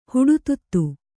♪ huḍututtu